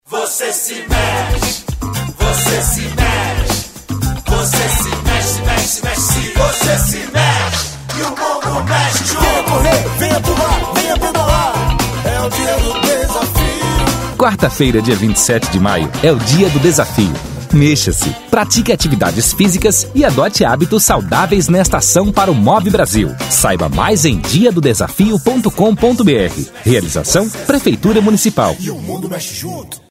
Chamada do Dia do Desafio.